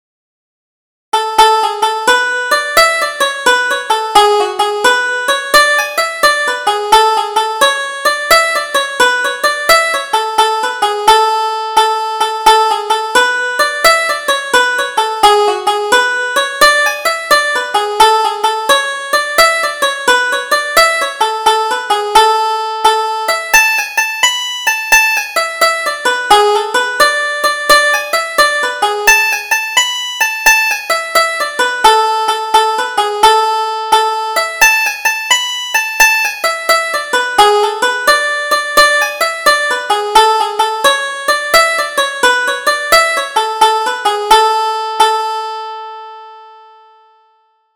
Double Jig: The Jolly Old Man